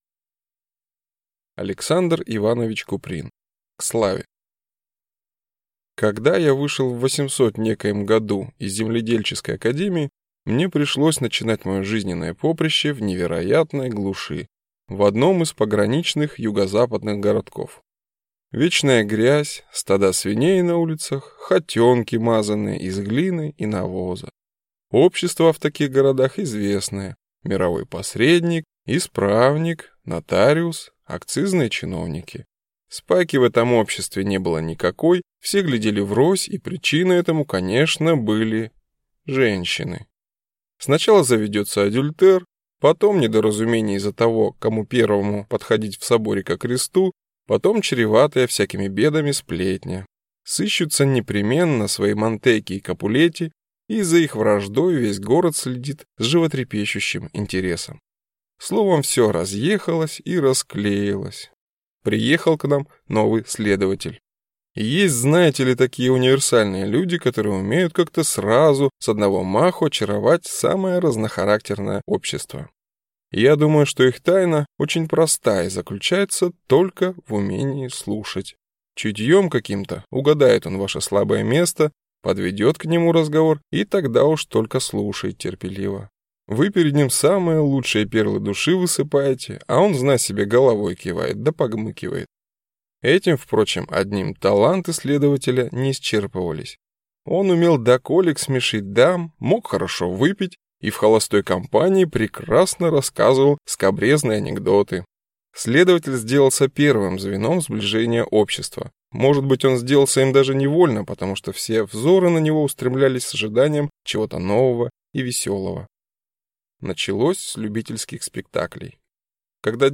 Аудиокнига К славе | Библиотека аудиокниг